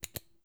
double-click.wav